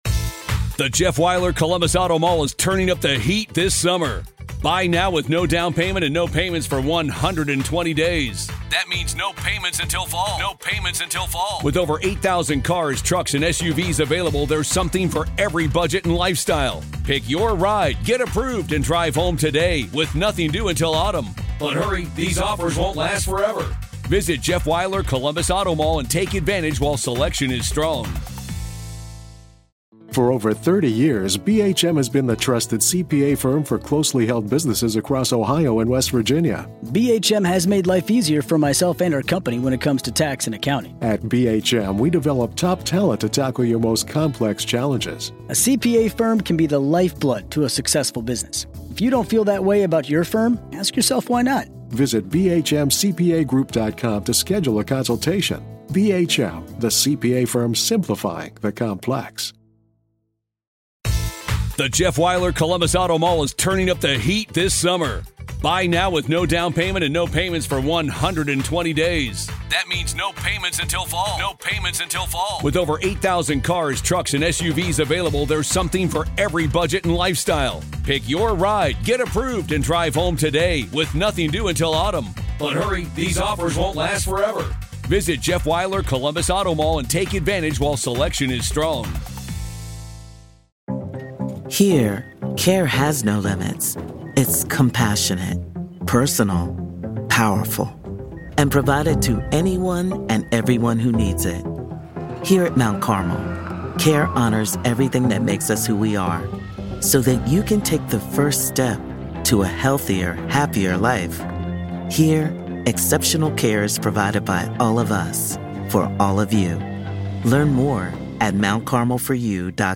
civil‑conversation style breakdown